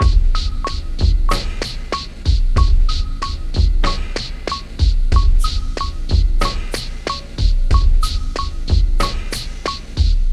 foreword drum break 93bpm.wav